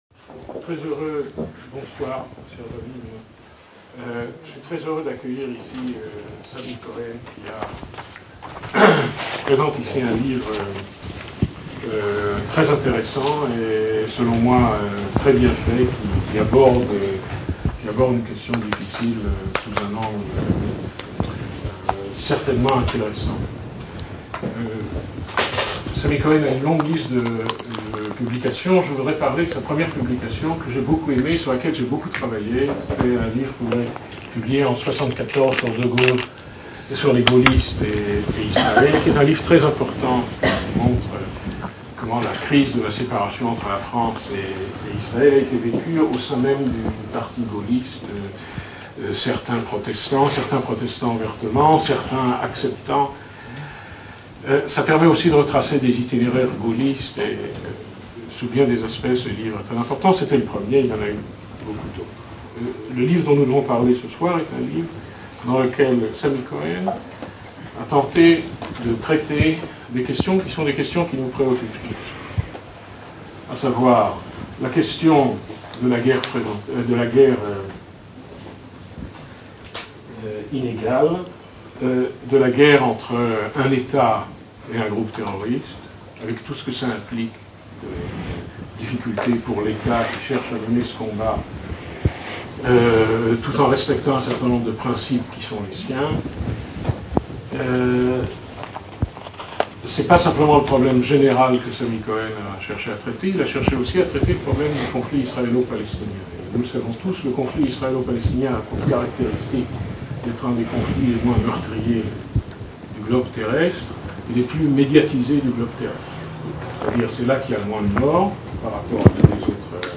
Conférence : Tsahal à l’épreuve du terrorisme
Conférence donnée à l’Institut français de Tel-Aviv en octobre 2009